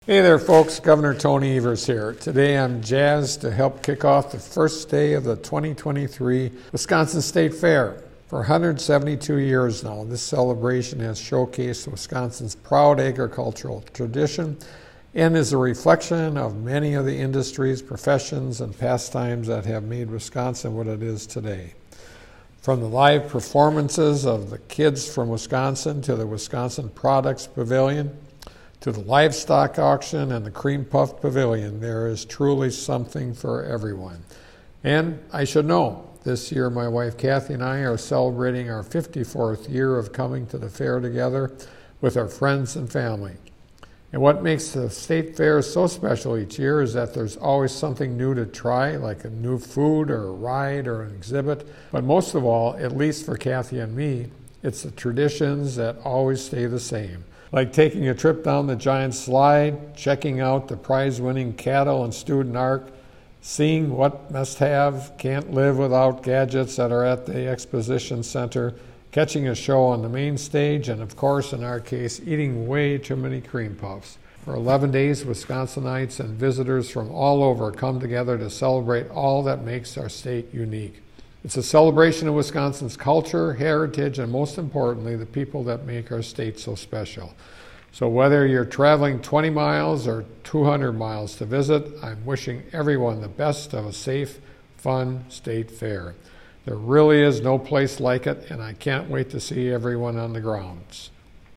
MADISON — Gov. Tony Evers today delivered the Democratic Radio Address kicking off the first day of the 172nd Wisconsin State Fair.